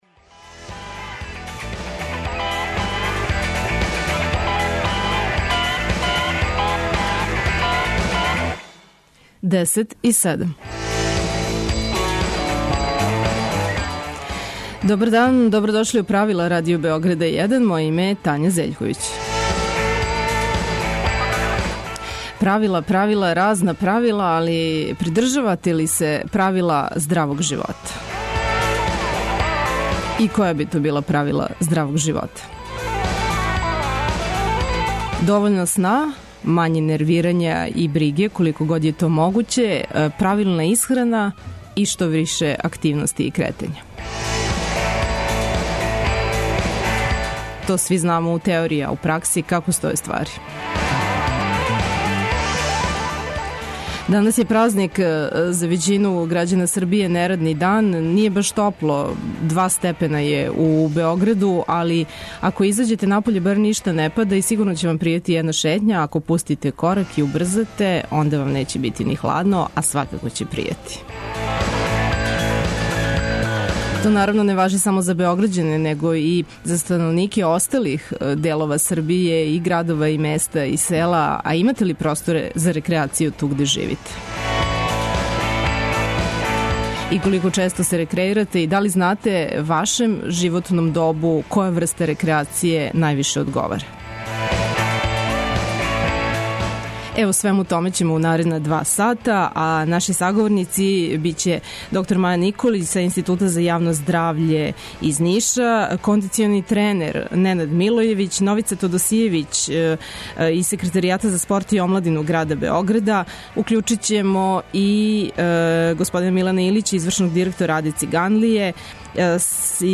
Путем телефона укључићемо докторку